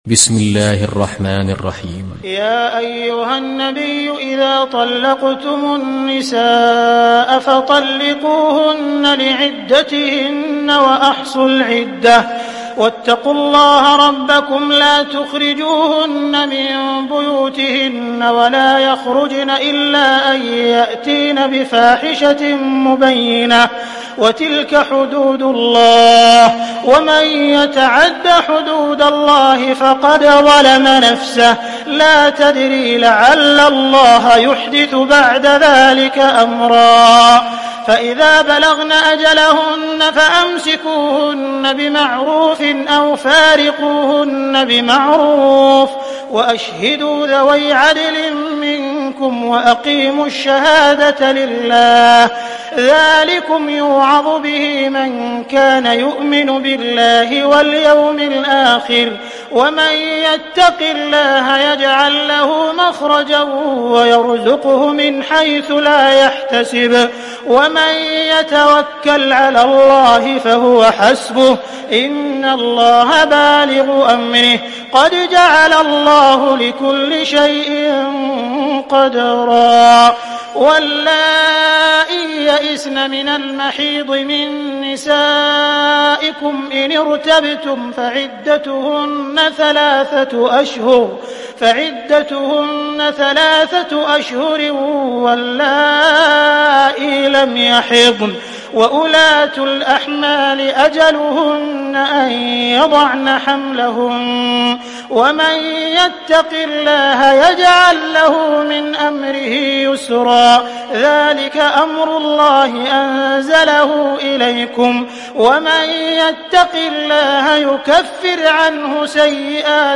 دانلود سوره الطلاق mp3 عبد الرحمن السديس روایت حفص از عاصم, قرآن را دانلود کنید و گوش کن mp3 ، لینک مستقیم کامل